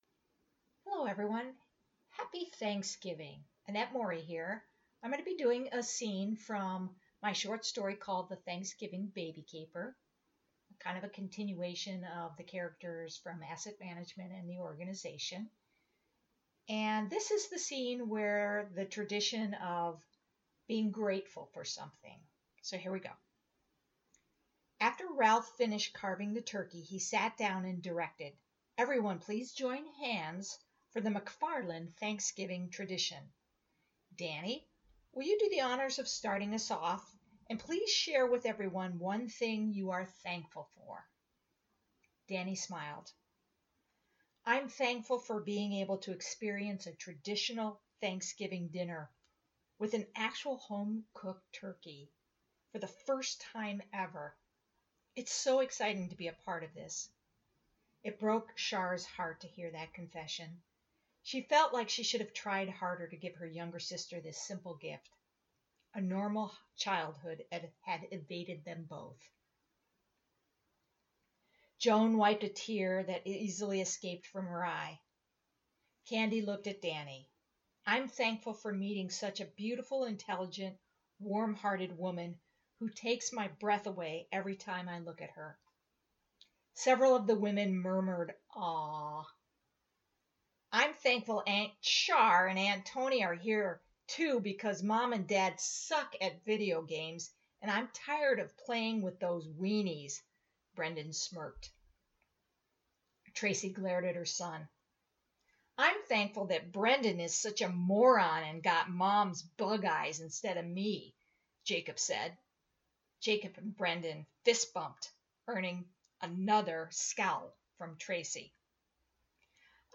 The Thanksgiving Baby Caper Reading
tbc-reading.mp3